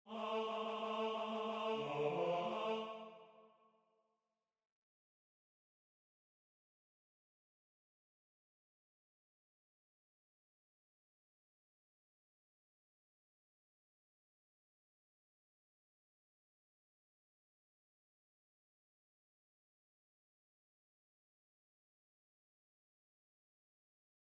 MP3 rendu voix synth.
Voix